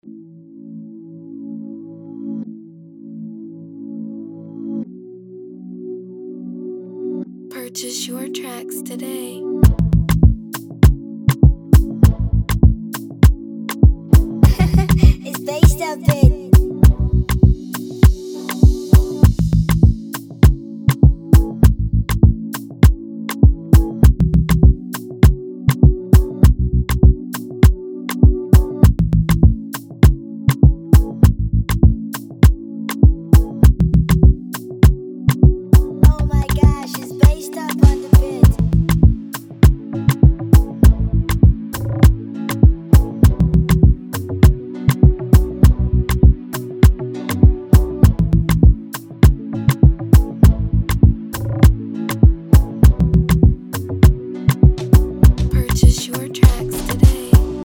a 100 bpm beat time and a D Major key